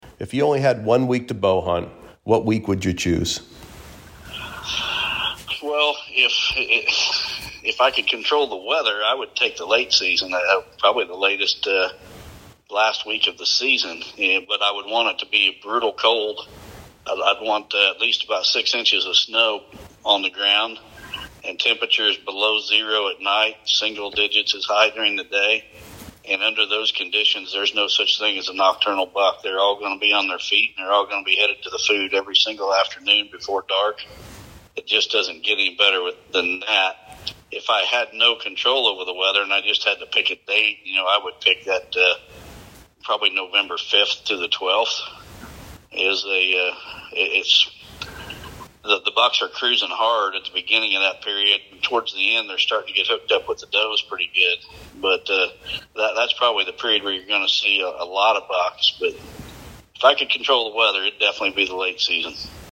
an exclusive Bowsite interview